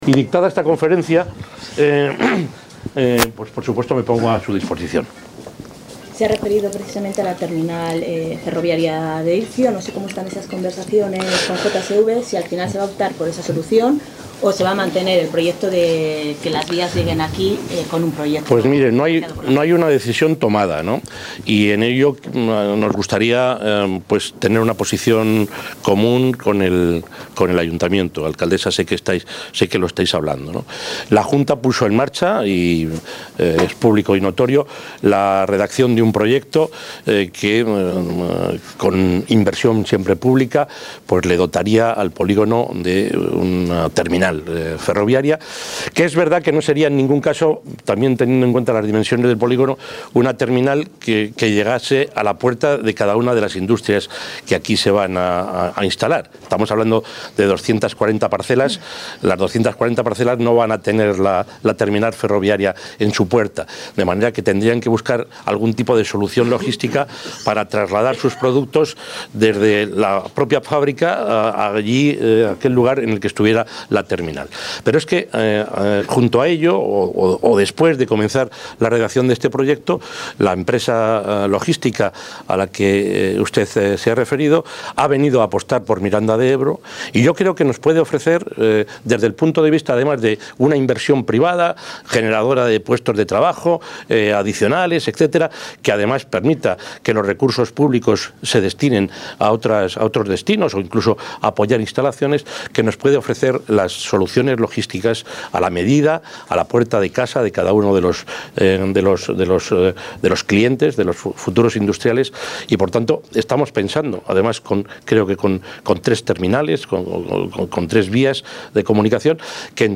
Declaraciones del presidente de la Junta de Castilla y Léon.
Declaraciones del presidente de la Junta de Castilla y Léon Atención a medios de comunicación Visita del presidente de la Junta a las nuevas instalaciones de...